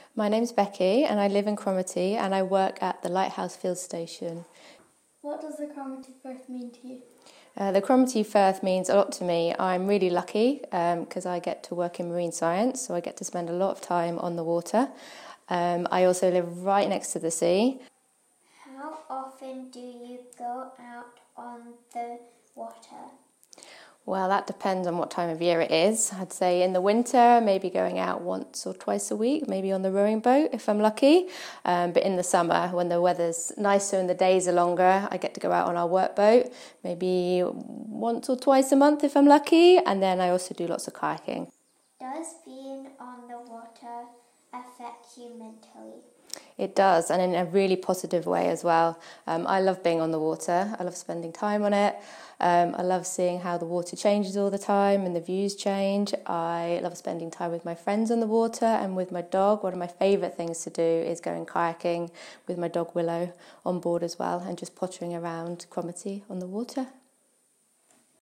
We worked with Cromarty Youth Cafe to record local people’s thoughts about the changes that are happening in our firth. The Youth Cafe came up with interview questions, and posed them to each other and to members of the local community.